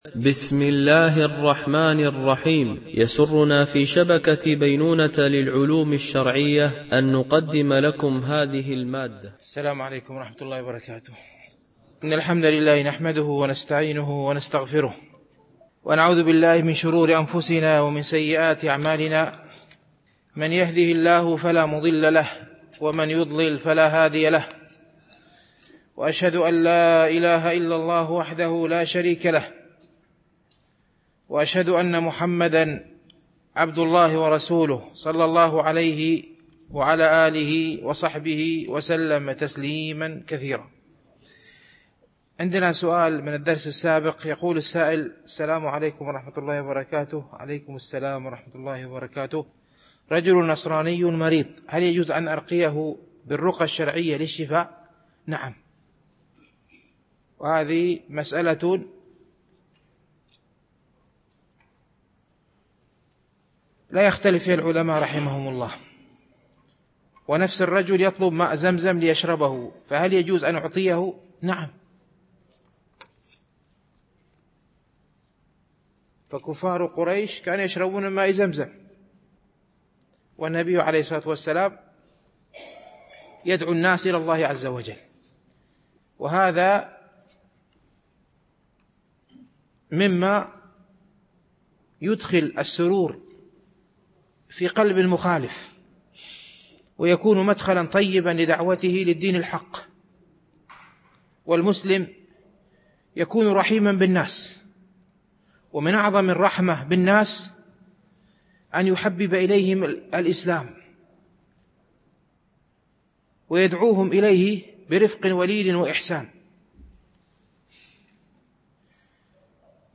شرح رياض الصالحين – الدرس 271 ( الحديث 1042 - 1045 )